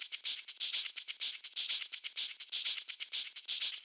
Live Percussion A 10.wav